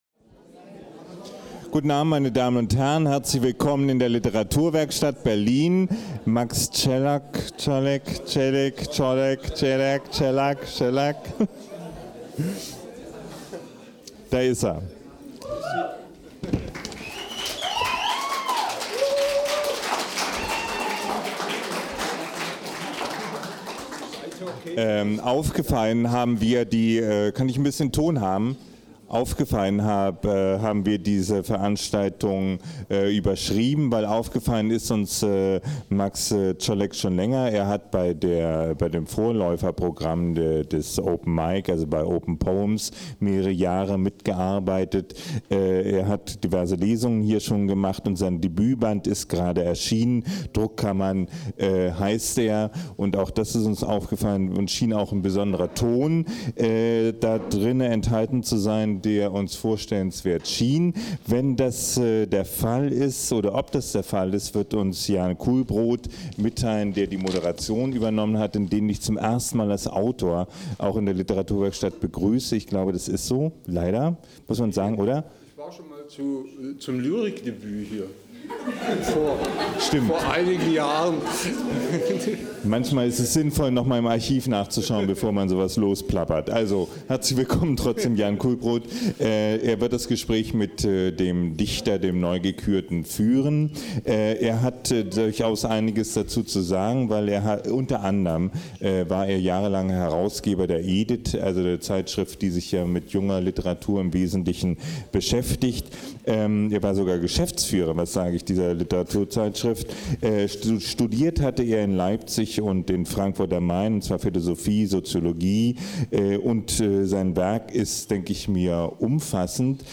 Ein Mitschnitt der Präsentation in der Literaturwerkstatt Berlin vom 18.4.12